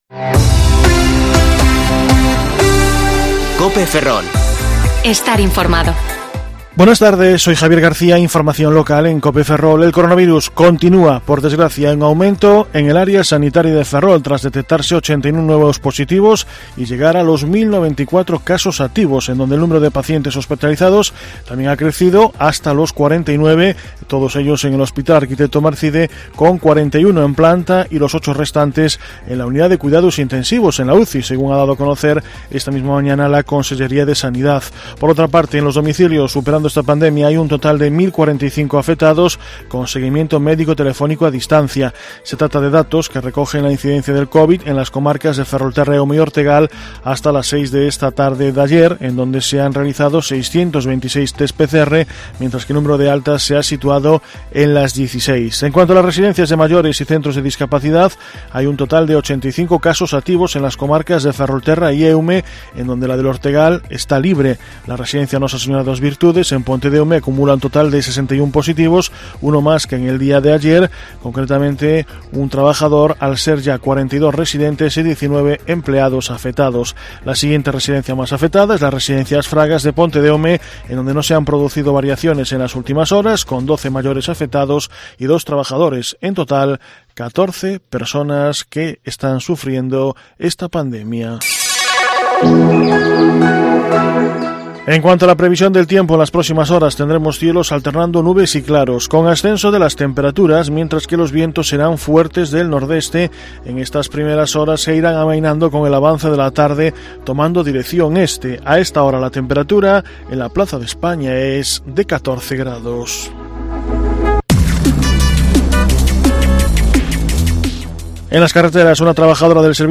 Informativo Mediodía COPE Ferrol 5/11/2020 (De 14,20 a 14,30 horas)